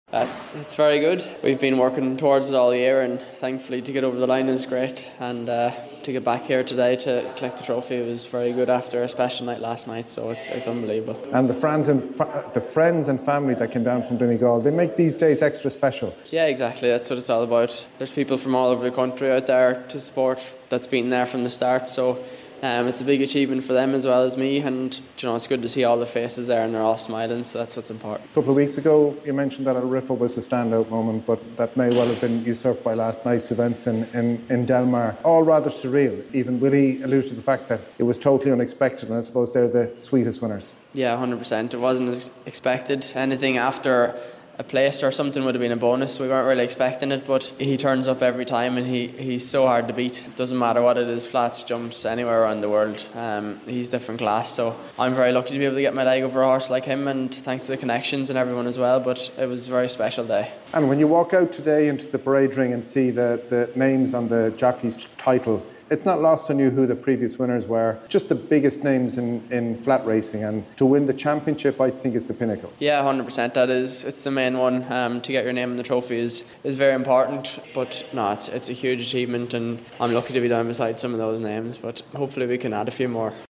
spoke with Dylan Browne McMonagle at The Curragh.